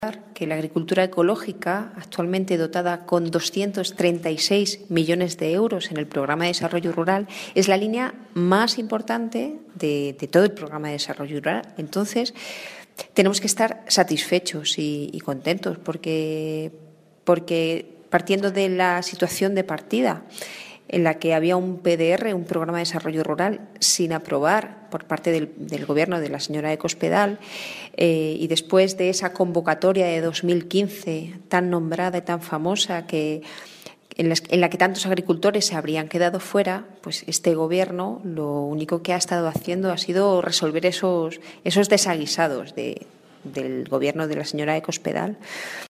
La diputada del Grupo Parlamentario Socialista en las Cortes de Castilla-La Mancha, Rosario García, se ha mostrado "muy satisfecha" con los datos ofrecidos esta semana por el Gobierno regional sobre el aumento de los fondos para la agricultura ecológica para los próximos ejercicios.
Cortes de audio de la rueda de prensa